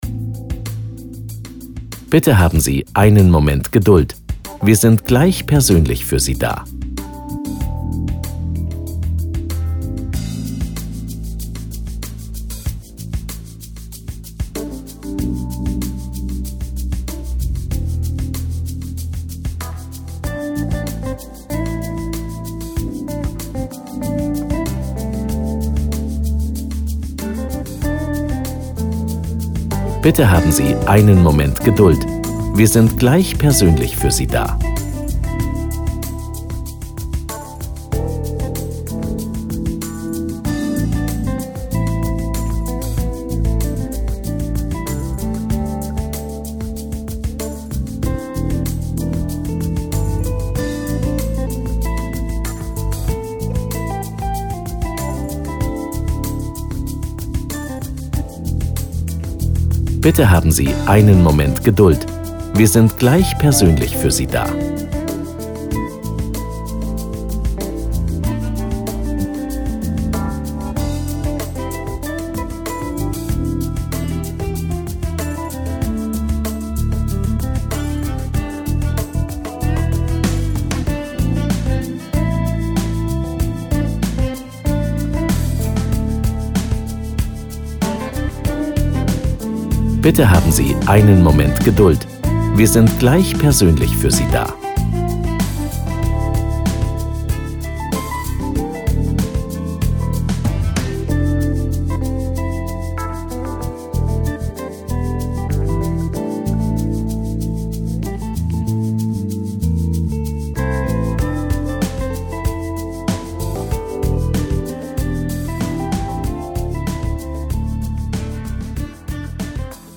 Telefonansagen mit echten Stimmen – keine KI !!!
Warteschleife – Hörbeispiel: